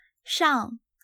shàng
シャン